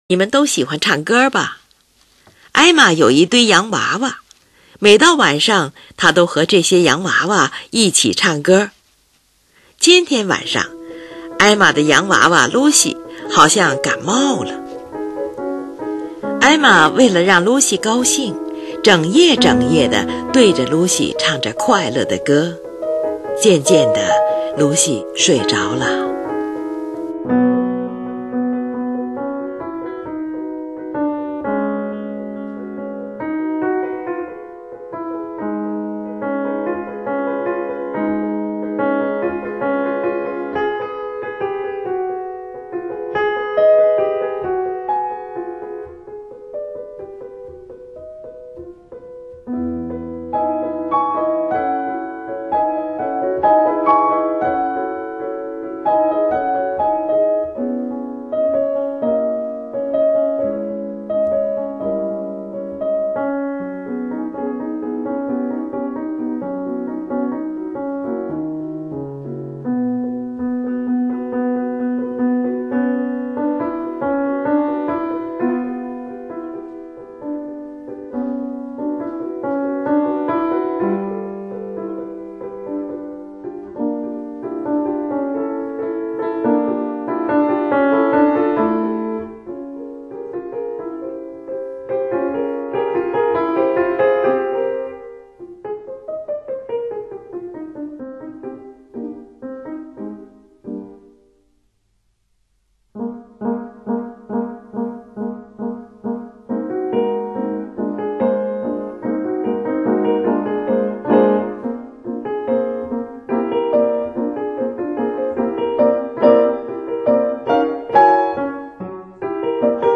伴奏中的五度分解，象在模仿弦乐器拨弦的音响声，节奏很密，显得很有动感。
在它之上，一首朴实而甜蜜的献给洋娃娃的小夜曲正在演奏。